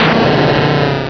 pokeemerald / sound / direct_sound_samples / cries / granbull.aif